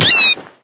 sounds / mob / bat / death.ogg
death.ogg